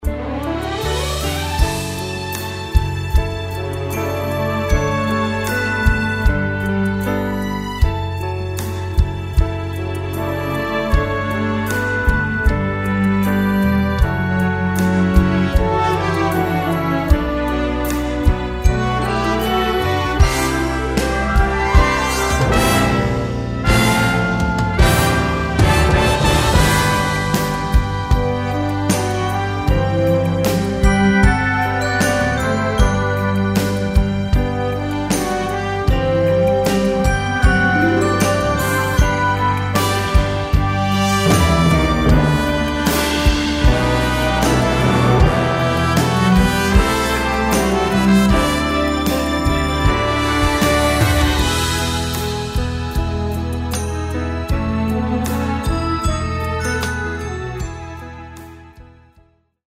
This is a professional performance track
Instrumental